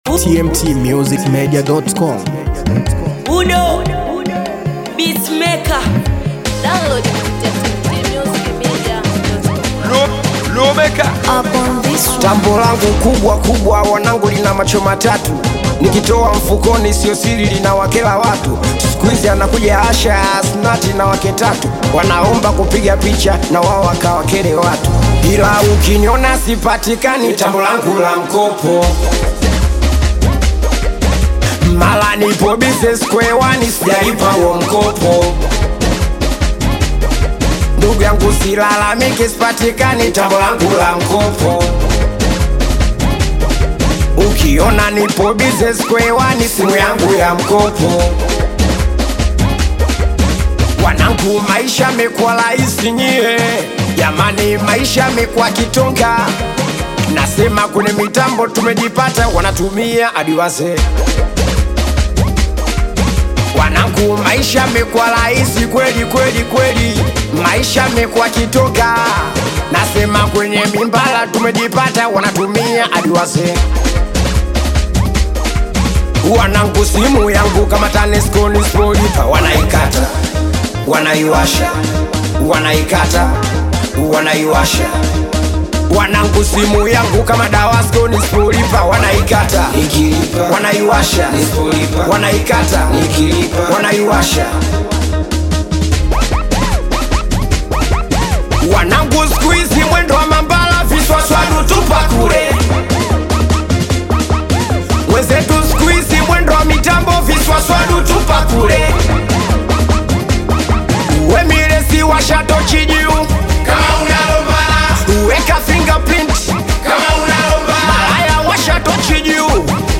Afro Beat
Singeli